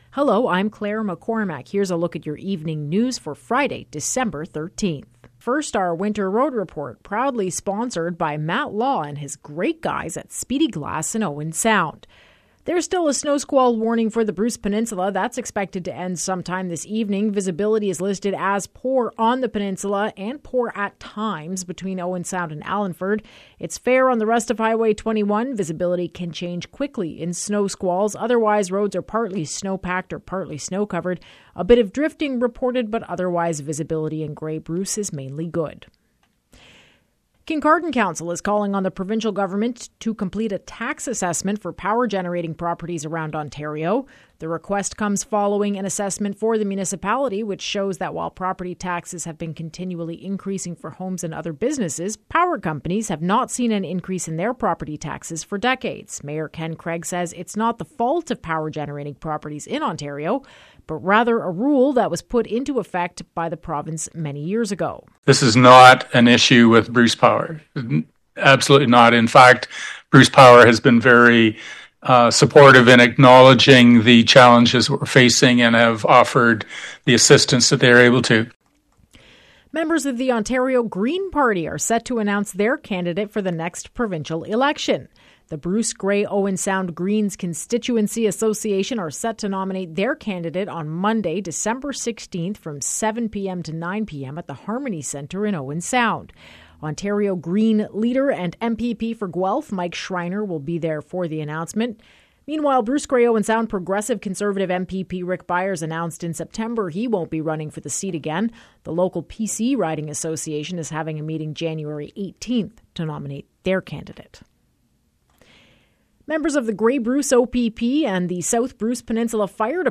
Evening News – Friday, December 13